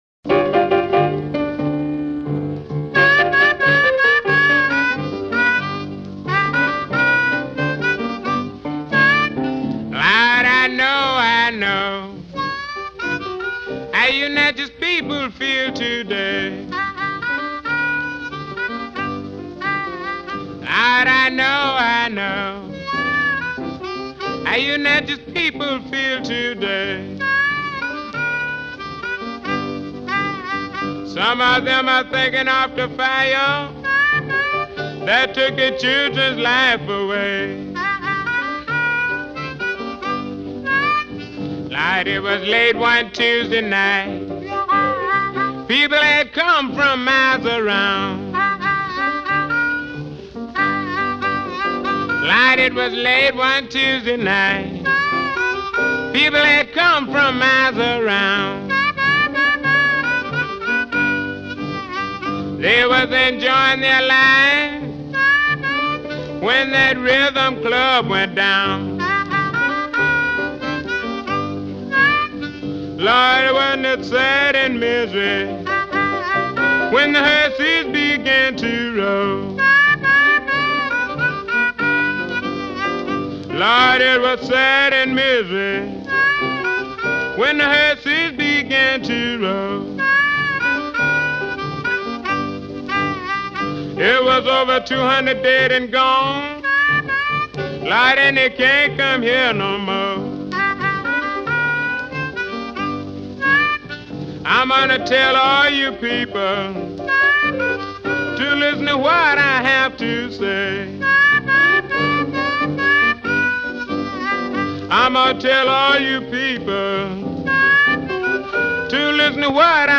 Vocal
Probably Harmonica
Piano